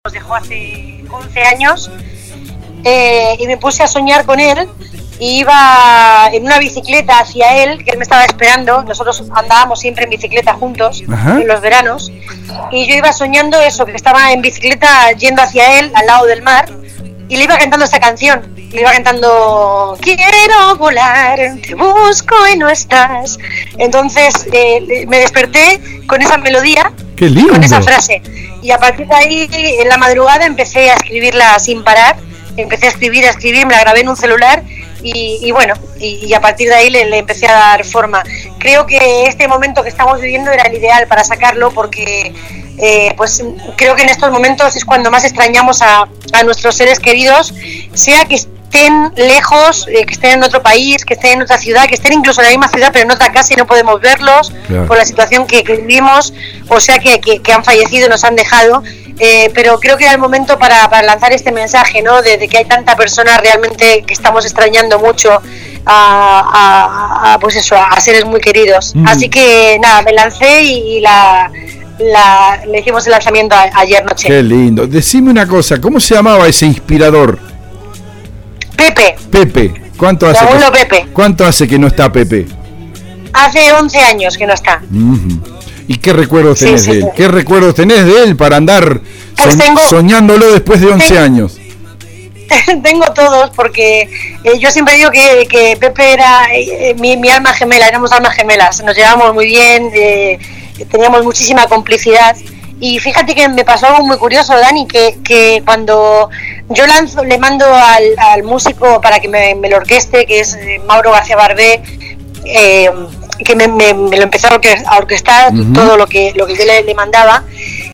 Entrevistamos a la artista española desde Madrid, en el programa Con Zeta de nuestra radio, a horas del lanzamiento.